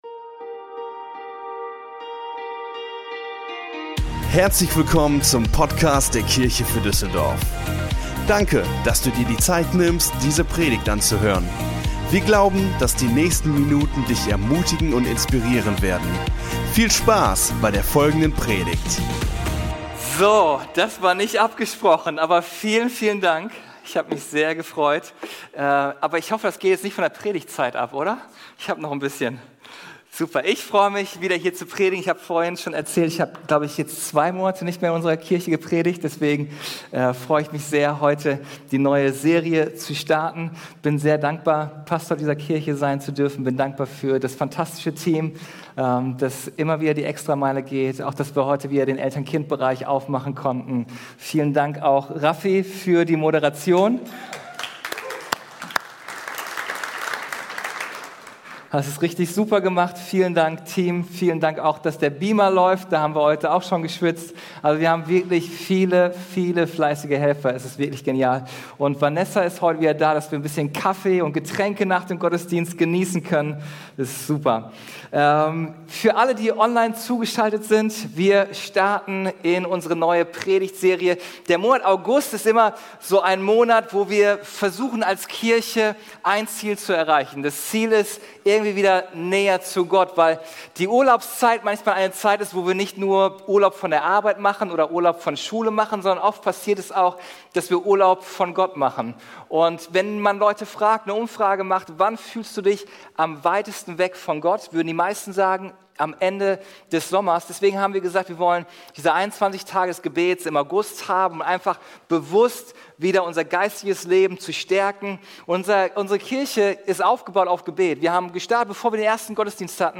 Der erste Teil unserer Predigtserie: "Gott begegnen" Folge direkt herunterladen